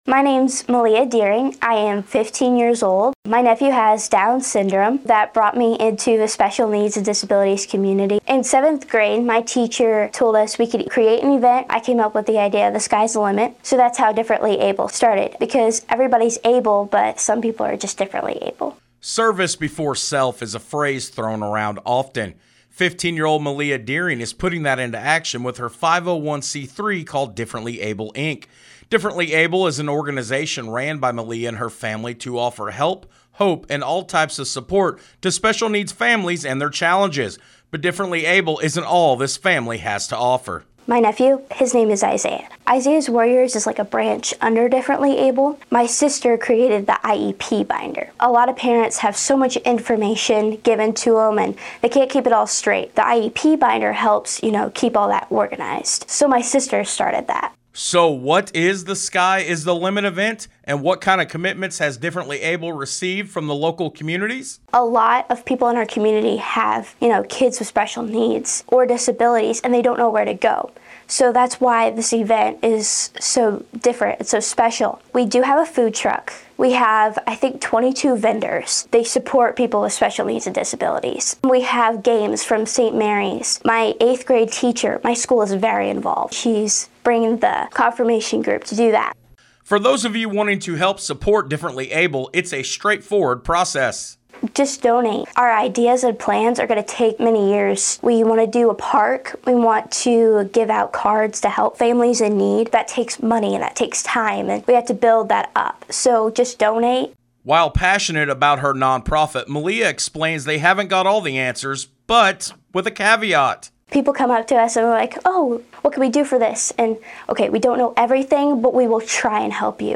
Best Radio Investigative ReportingWBDC-FM (Huntingburg)– Differently Able